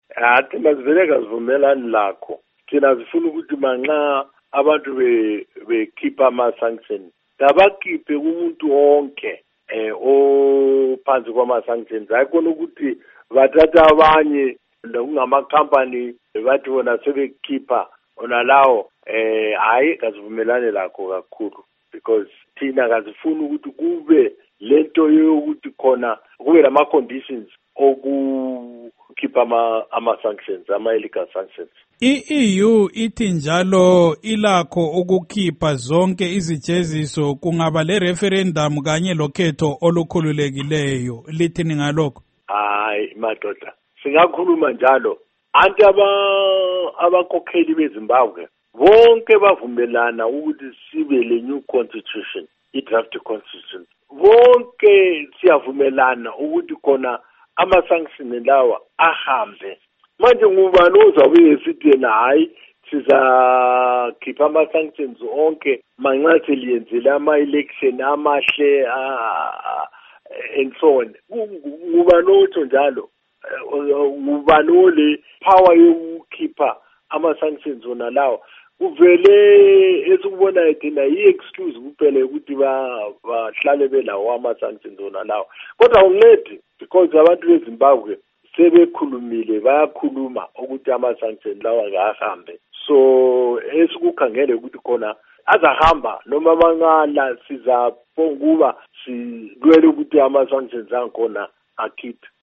Ingxoxo LoMnu Rugare Gumbo